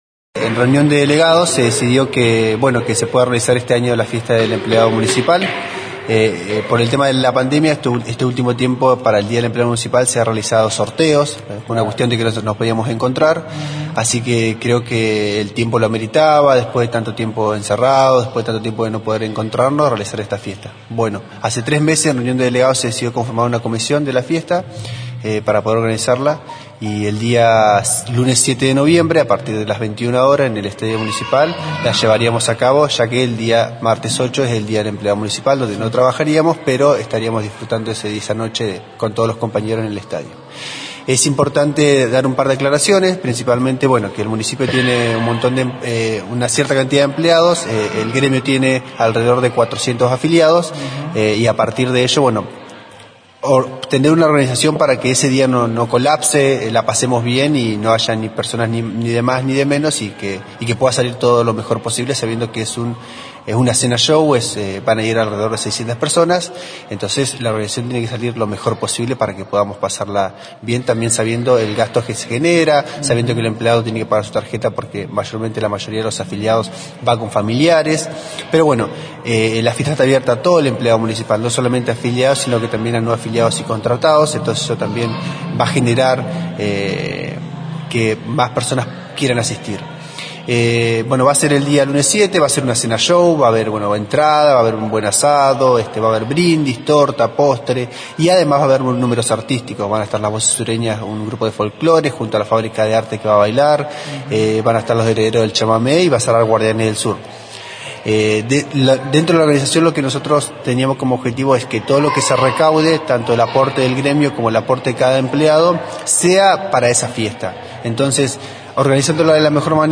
En el Sindicato de Obreros y Empleados Municipales de Esquel y Zona Oeste se realizó una conferencia de prensa, para anunciar las actividades enmarcadas en el Día del Empleado Municipal.